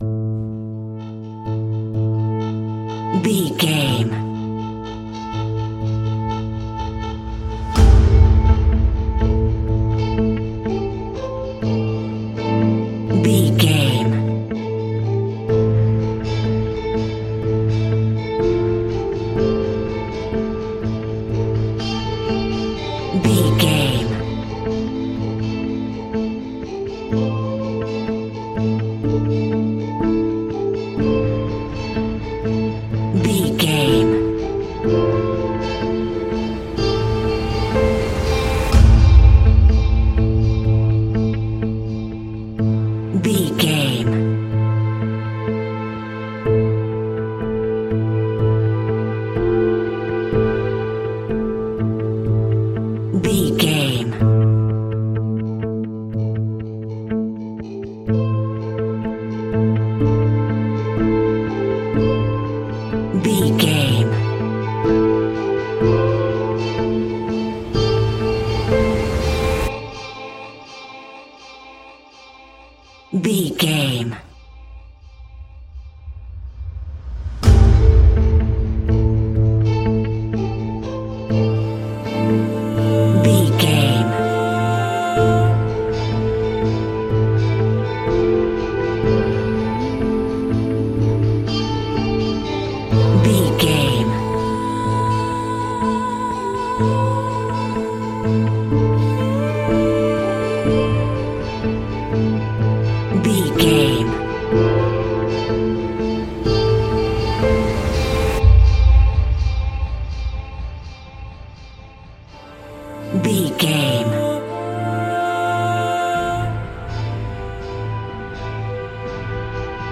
Ionian/Major
A♭
electronic
techno
trance
synths
synthwave
instrumentals